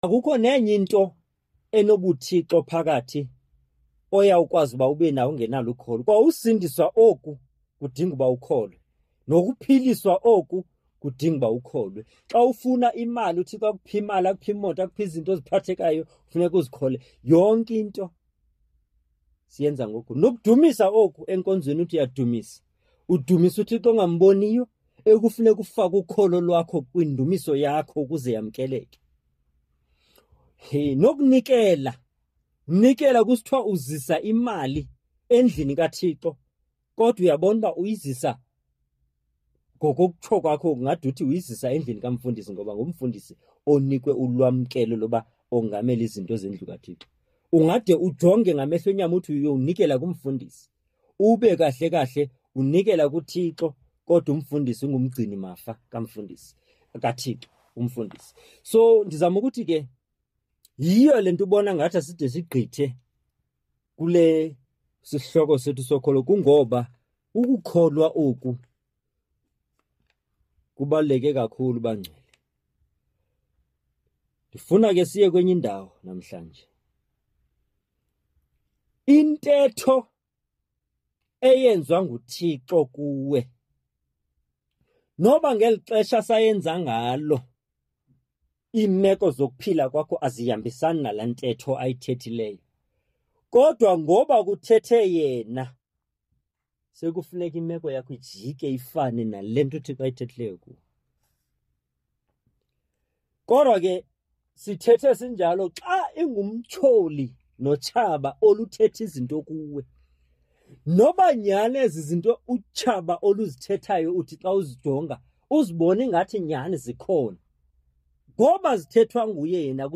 Radio Pulpit Program where I share God's word through Preaching, Teaching and Motivation using my mother tongue (isiXhosa)